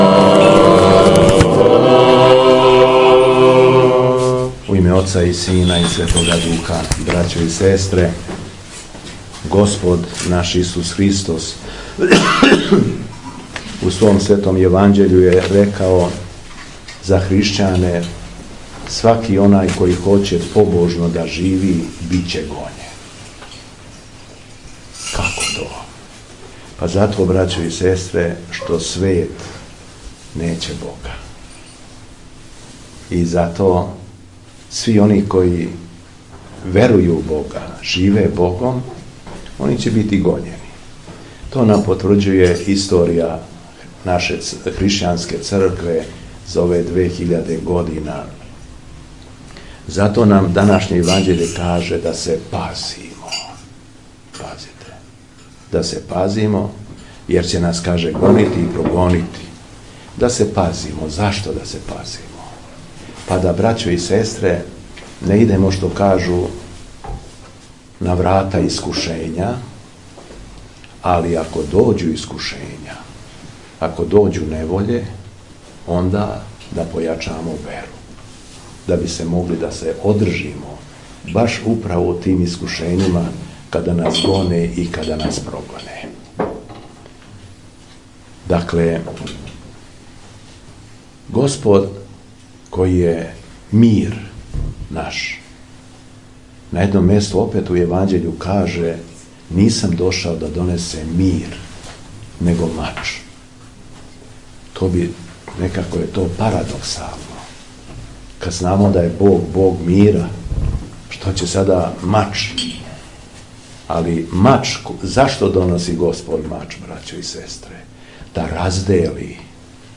Беседа Његовог Преосвештенства Епископа шумадијског г. Јована